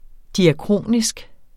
Udtale [ diaˈkʁoˀnisg ]